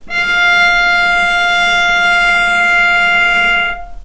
vc-F5-mf.AIF